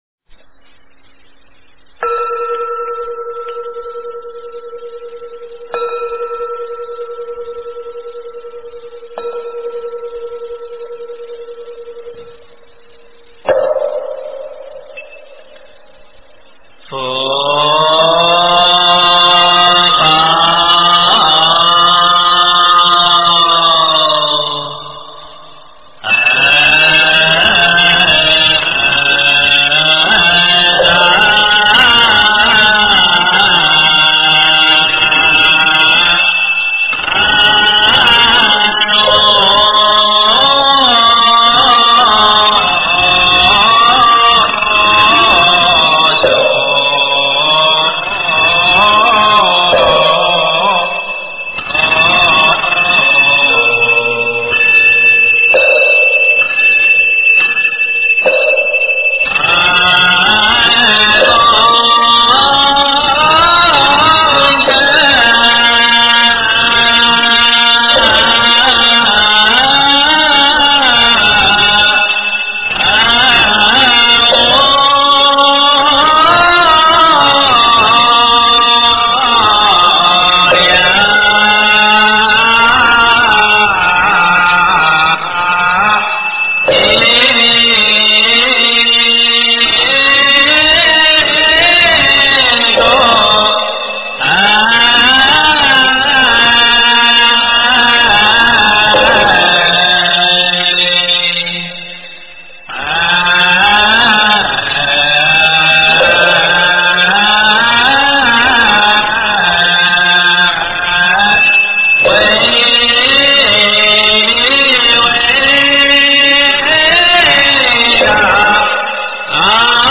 经忏
佛音 经忏 佛教音乐 返回列表 上一篇： 蒙山施食--天宁寺 下一篇： 佛说阿弥陀经--僧团 相关文章 南无消灾延寿药师佛--心定和尚 南无消灾延寿药师佛--心定和尚...